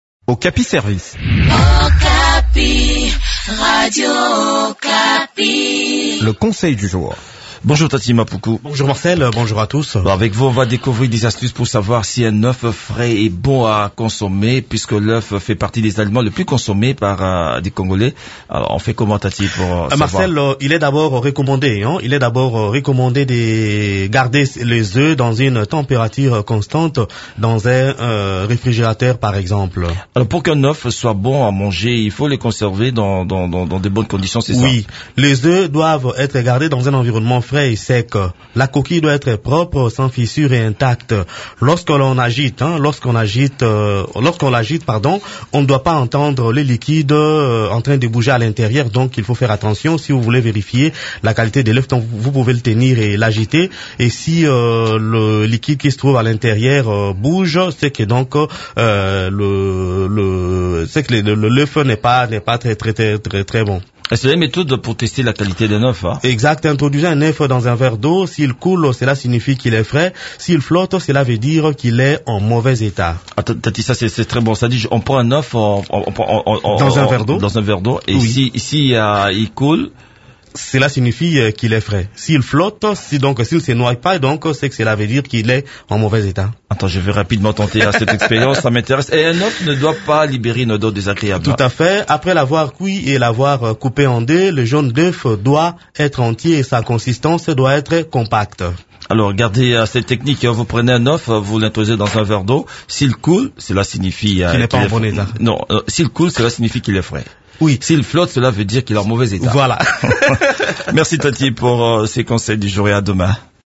Découvrez des astuces qui peuvent vous aider à savoir si l’œuf de poule est frais et bon à consommer dans cette chronique